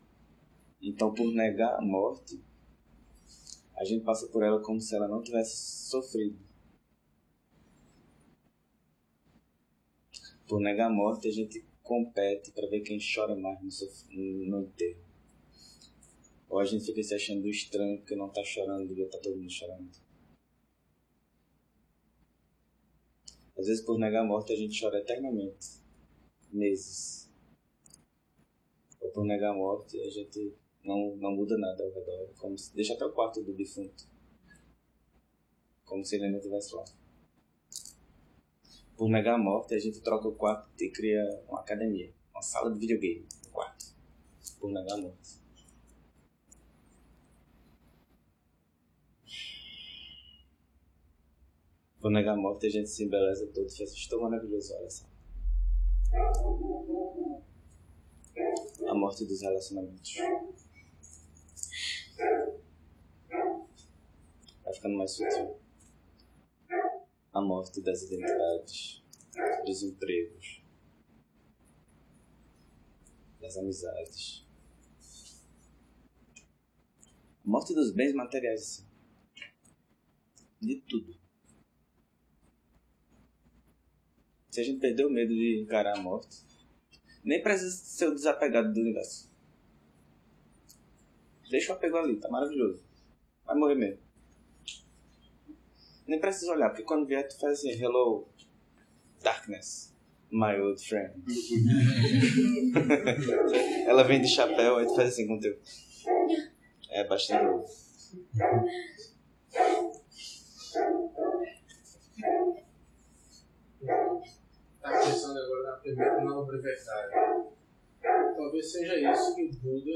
Encontro ocorrido no CEBB Recife, sala encruzilhada em 14 de fevereiro de 2019. Ciclo de estudo: A operação da mente na visão budista através dos 12 elos.